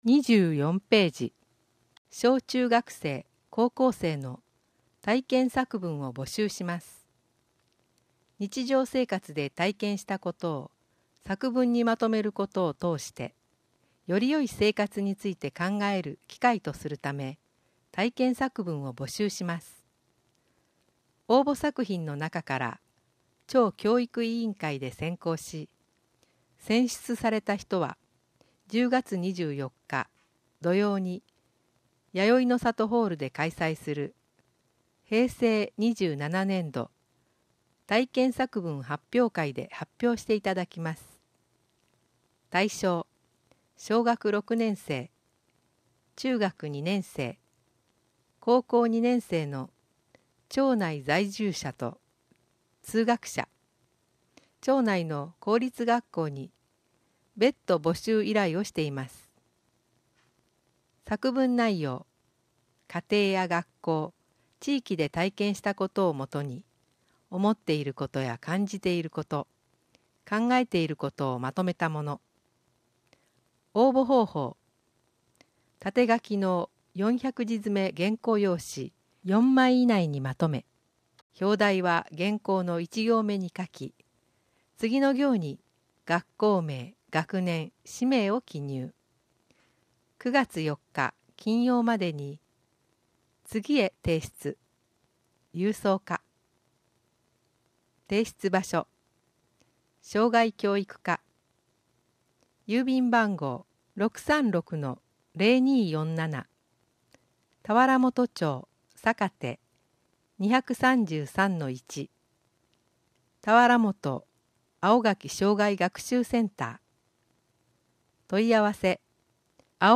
2015年8月14日更新 議会だより 議会だより (PDFファイル: 664.3KB) 音訳議会だより (音声ファイル: 12.5MB) 本紙 広報8月号 全ページ (PDFファイル: 7.8MB) 電子書籍版は下記のリンク先（別サイト）をご覧ください。